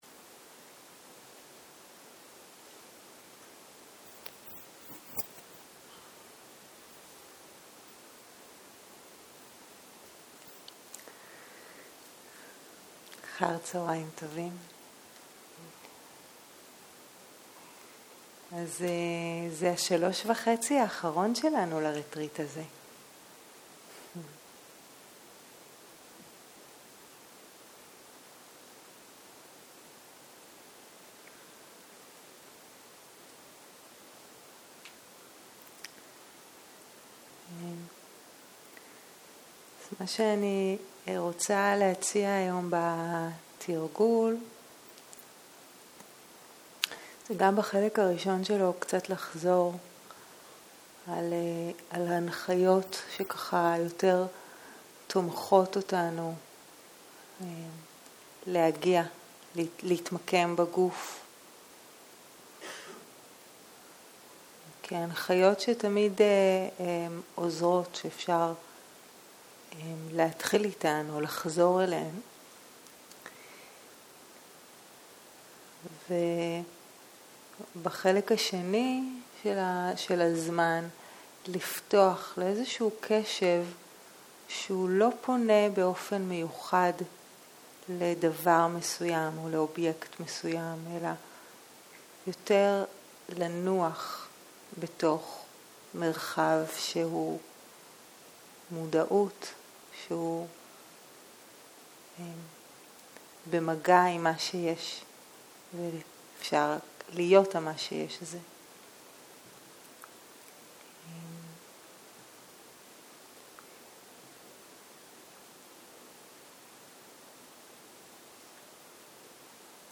צהריים - מדיטציה מונחית - שיחה 14